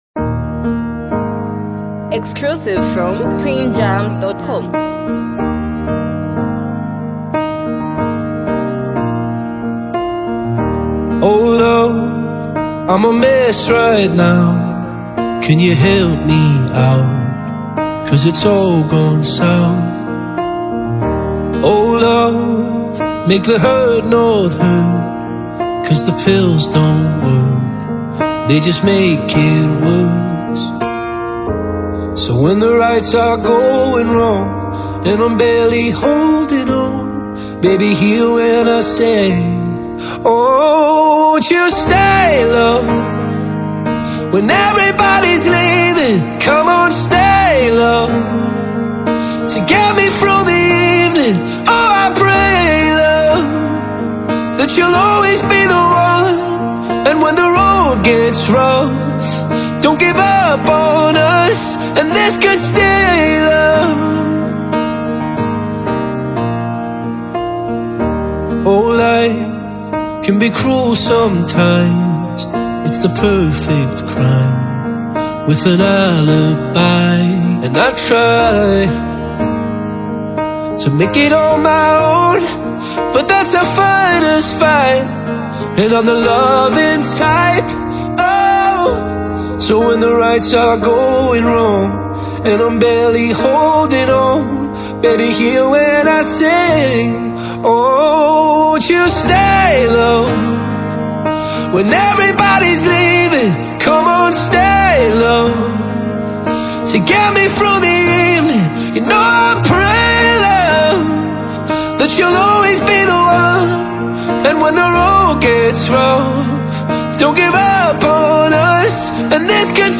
soft, piano-driven arrangement
ballad